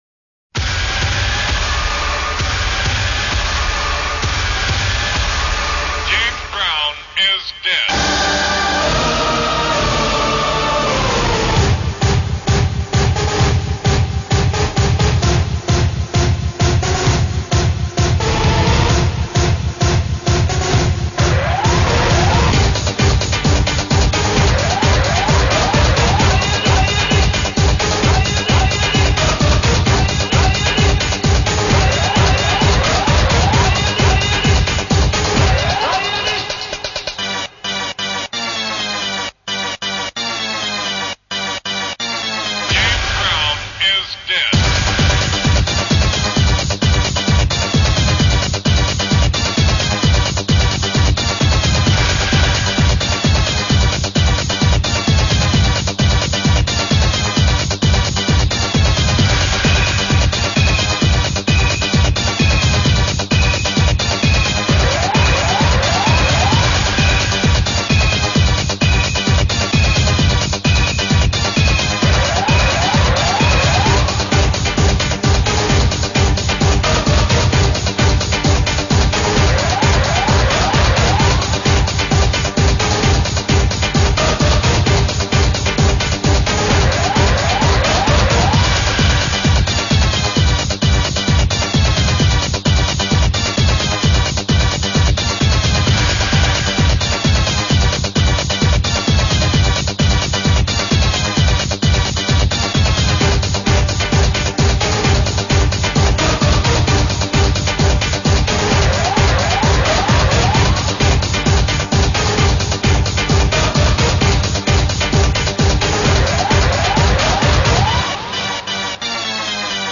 rave track
22kHz Mono